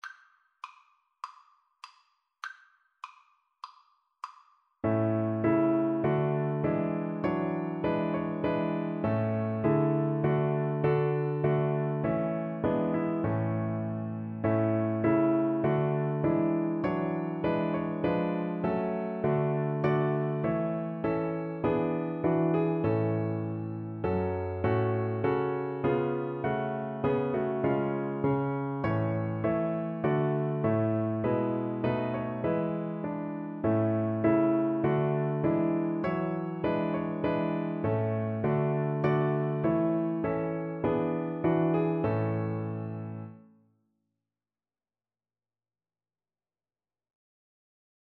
Cello
A major (Sounding Pitch) (View more A major Music for Cello )
4/4 (View more 4/4 Music)
Classical (View more Classical Cello Music)